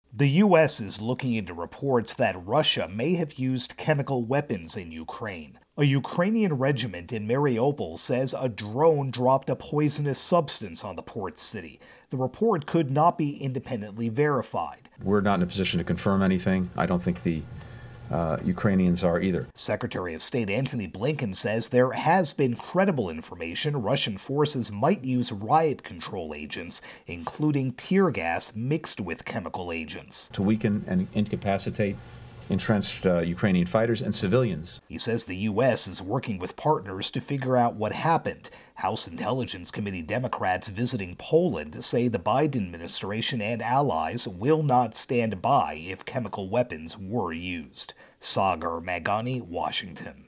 Russia-Ukraine War intro and wrap.